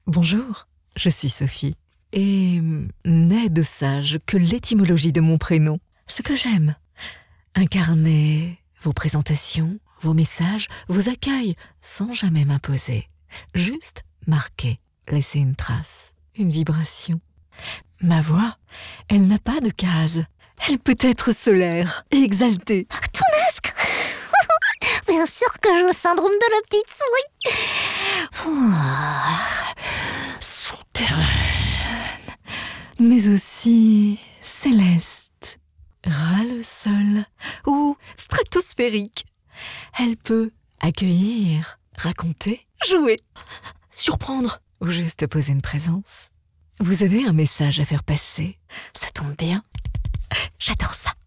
Voix off
Démo - Full range demo
Français natif (France) | Créole antillais | Anglais avec accent français authentique
Mezzo/alto chaude, posée, ancrée
Home Studio professionnel qualité broadcast